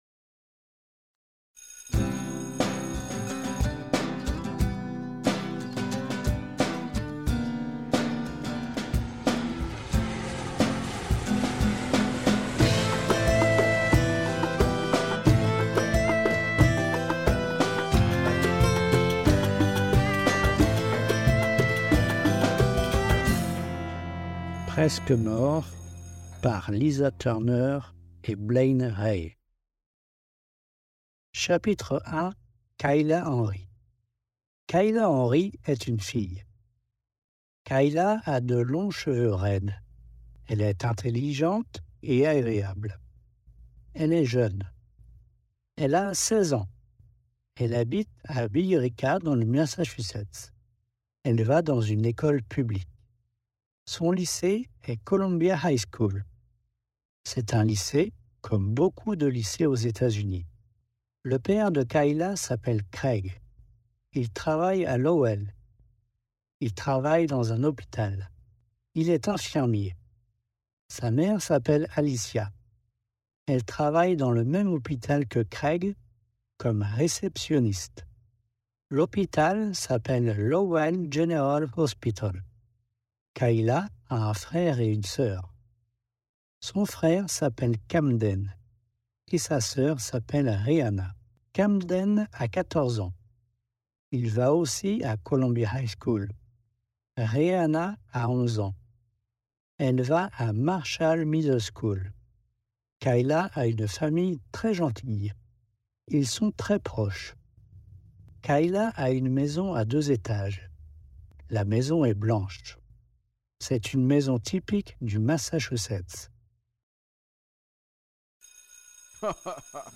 • original music
• sound effects and
• voice acting from Native Spanish speakers !
Audio Book Sample